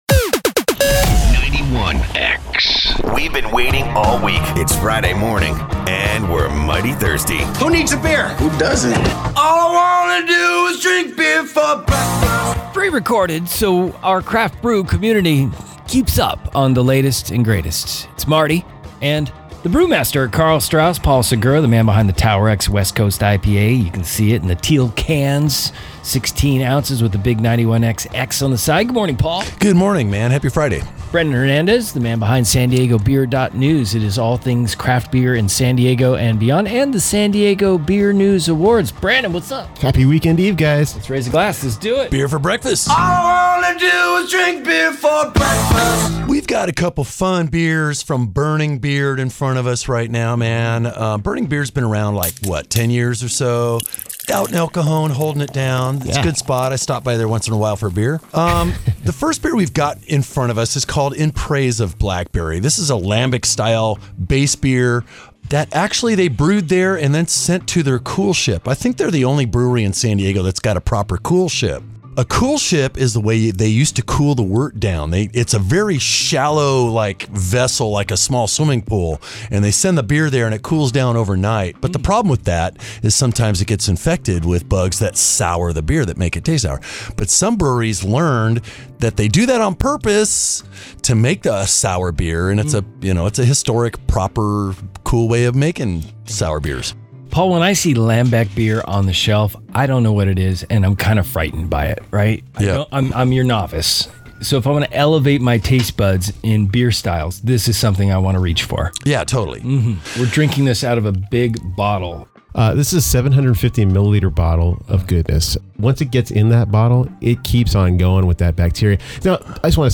Listen to the crew describe a pair of “The Beard’s” exceptional creations, a fruited lambic called In Praise of Blackberry, and an imperial stout aged in Spanish vermouth barrels with almonds dubbed The Godfather Post Modern Prometheus. 91X Beer For Breakfast - Burning Beard